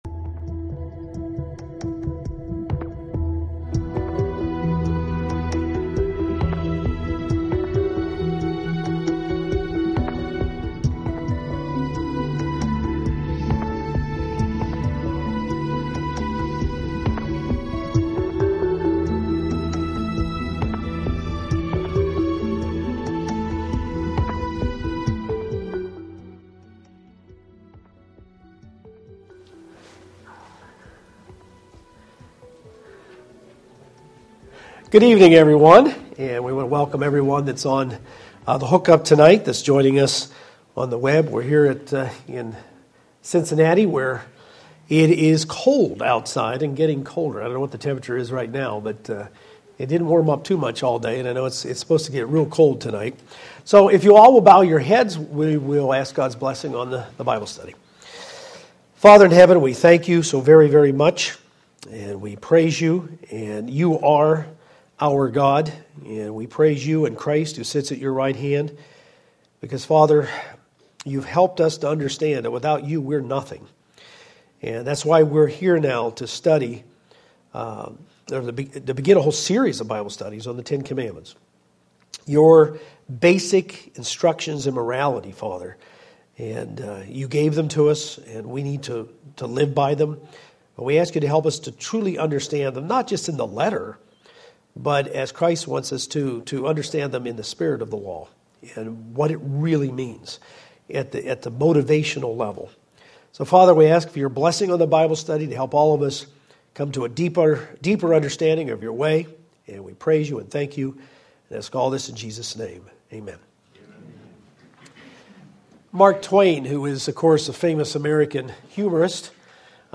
This is the first part in the Beyond Today Bible study series: The Ten Commandments. In the first of the Ten Commandments the God of Israel reveals that He is unique and personal. It is the basis for all of the other commandments.